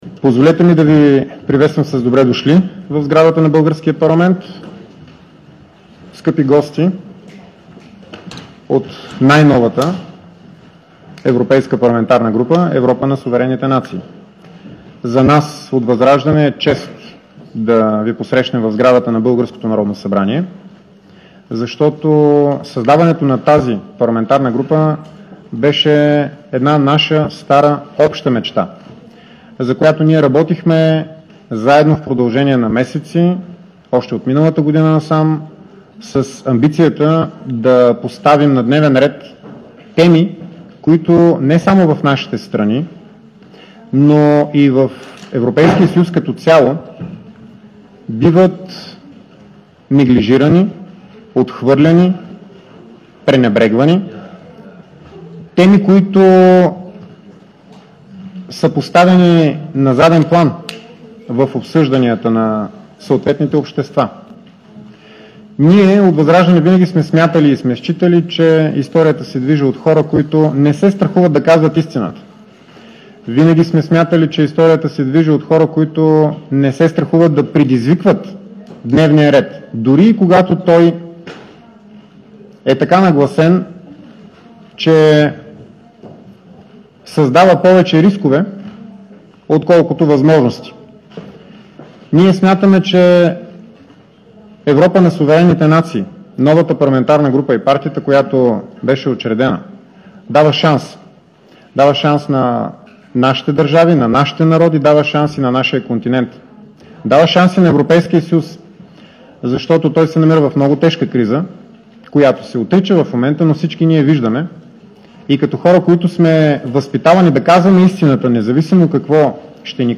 9.20 - Работна среща на тема „Парламентаризъм и демокрация" на групата на „Европа на суверенните нации"  и  народни представители от „Възраждане".
Директно от мястото на събитието